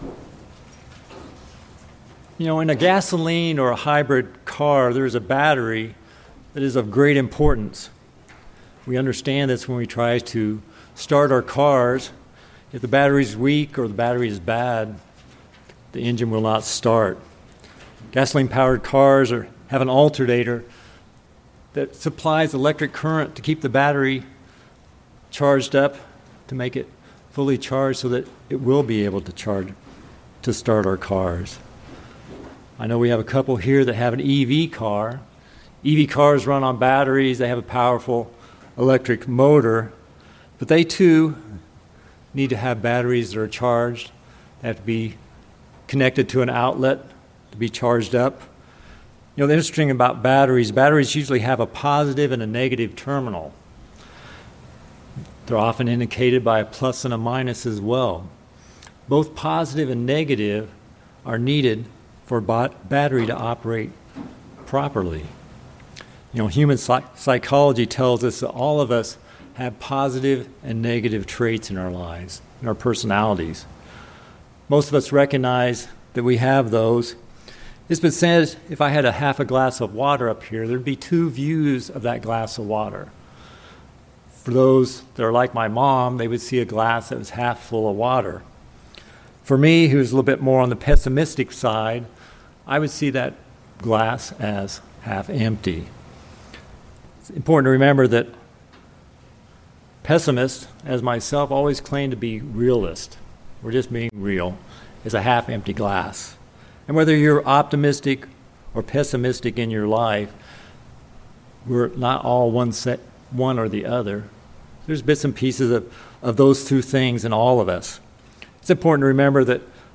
Just like batteries have positive and negative charges, people tend to look at the world from either a positive or negative perspective. This sermon will look at how we can stay positive in a negative world, which is a key to fulfilling what God is calling us to do.